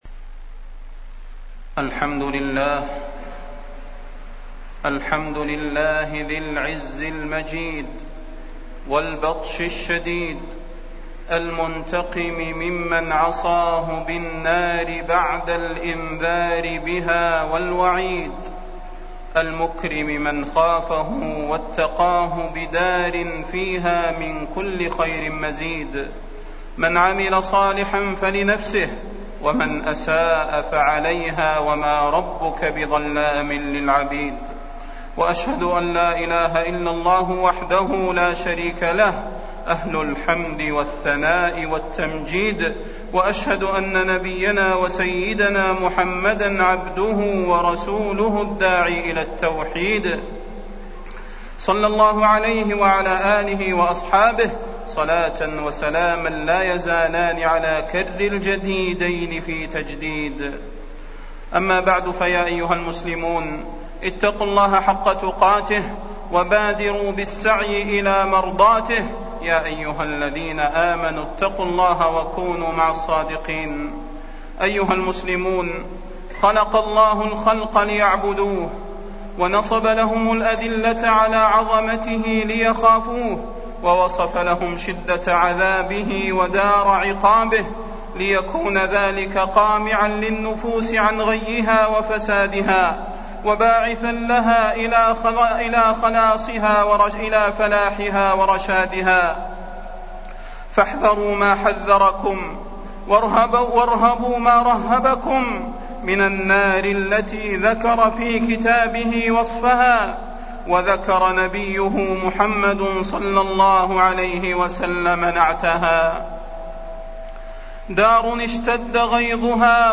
تاريخ النشر ٨ رجب ١٤٢٩ هـ المكان: المسجد النبوي الشيخ: فضيلة الشيخ د. صلاح بن محمد البدير فضيلة الشيخ د. صلاح بن محمد البدير صفة النار وأهلها